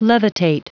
Prononciation du mot levitate en anglais (fichier audio)
Prononciation du mot : levitate